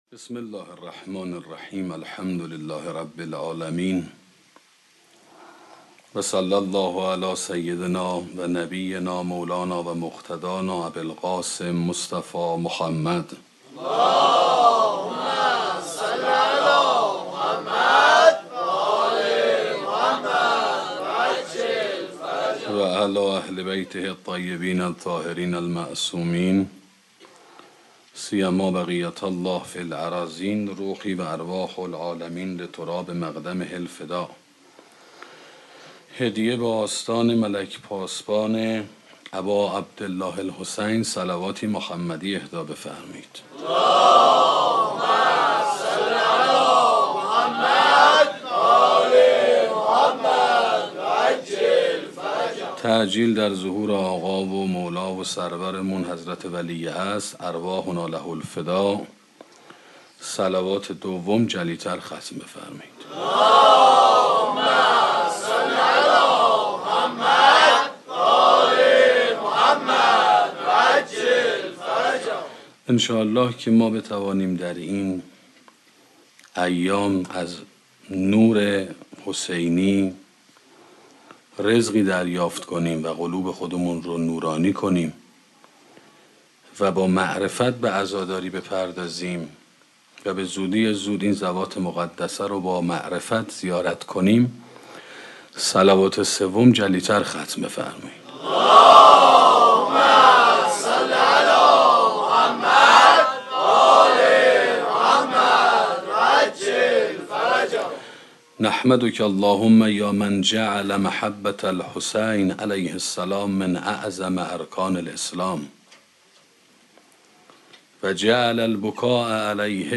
سخنرانی عشق متعالیه